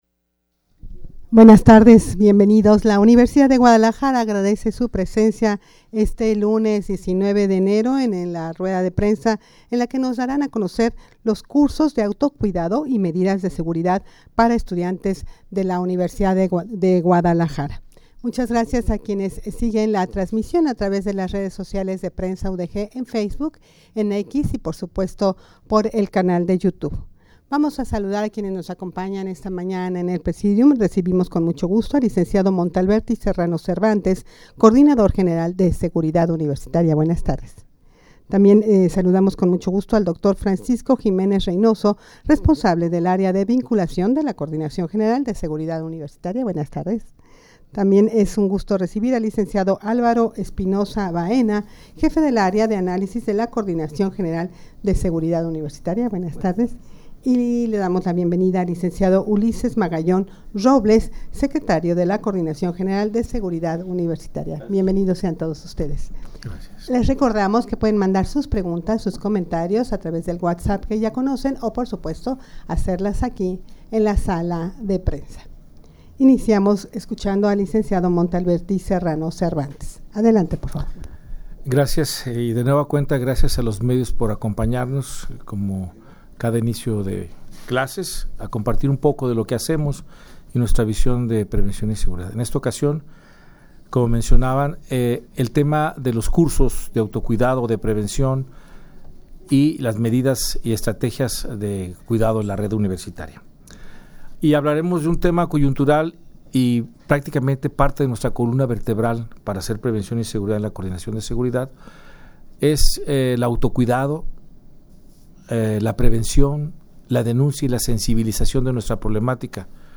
Audio de la Rueda de Prensa
rueda-de-prensa-cursos-de-autocuidado-y-medidas-de-seguridad-para-estudiantes-de-la-udeg.mp3